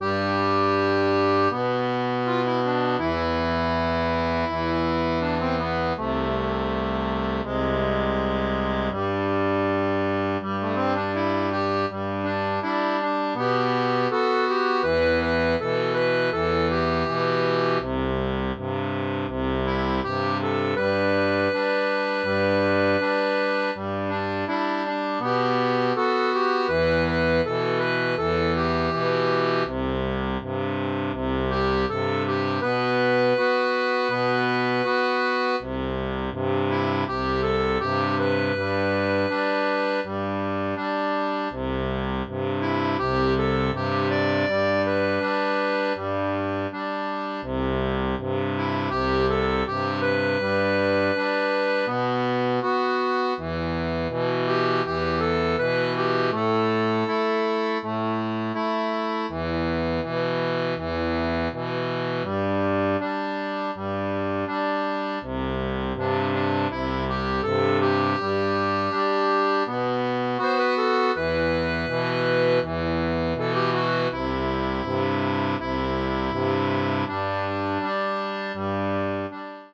Type d'accordéon
Chanson française